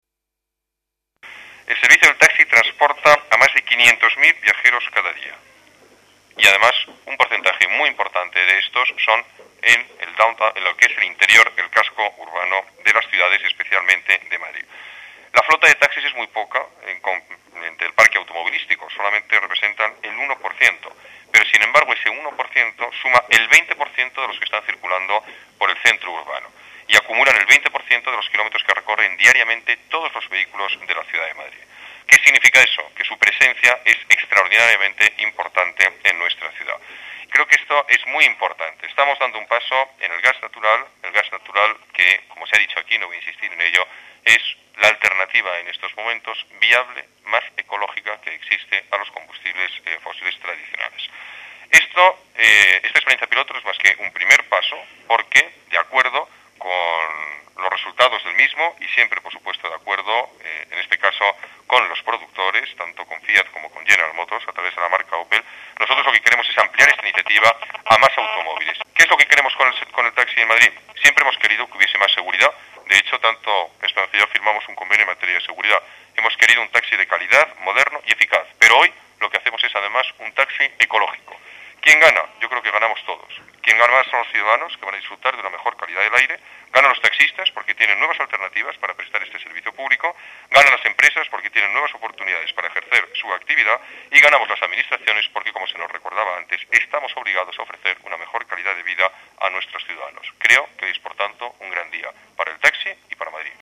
Nueva ventana:El Alcalde, Alberto Ruiz Gallardón, destaca la importancia de la utilización del gas natural como la alternativa viable más ecológica a los combustibles fósiles tradicionales